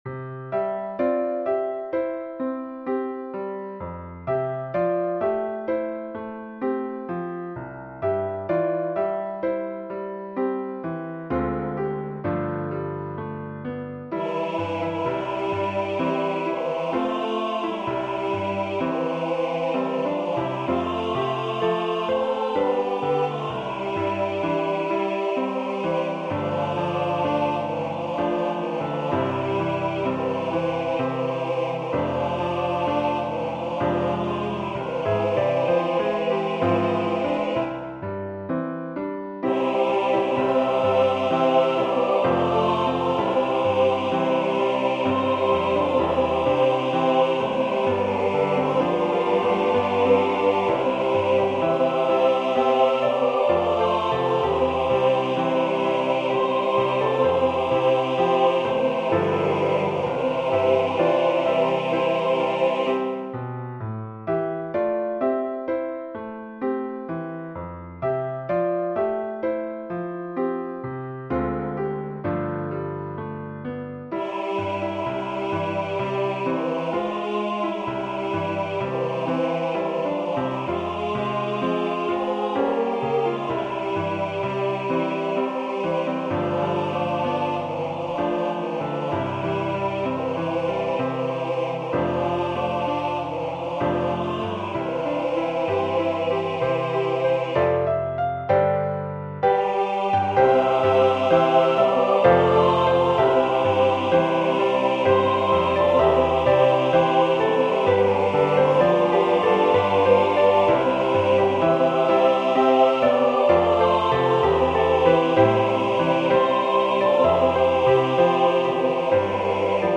SATB with Piano Accompaniment
Voicing/Instrumentation: SATB